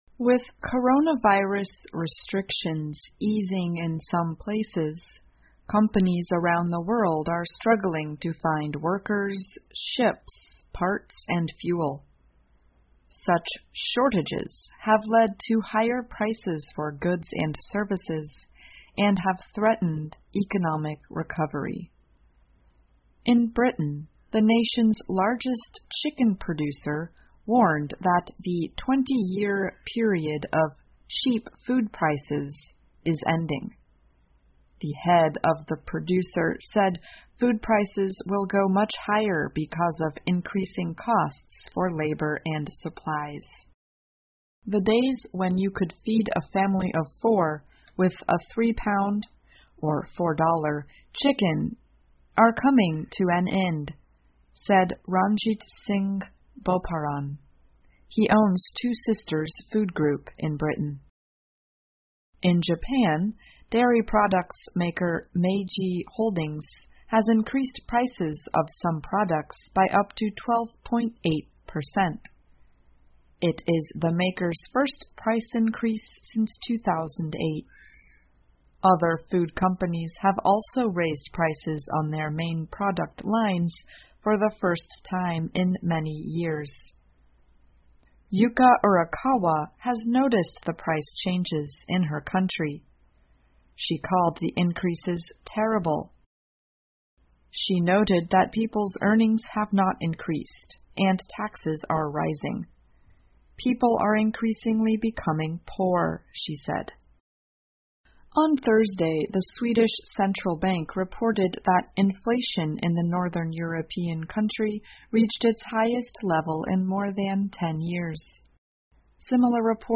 VOA慢速英语--世界各地的商品和服务成本都在上升 听力文件下载—在线英语听力室